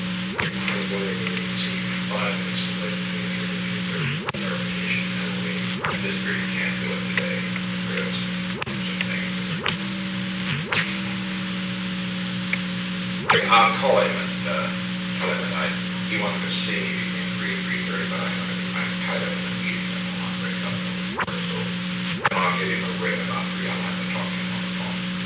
Secret White House Tapes
Conversation No. 430-5
Location: Executive Office Building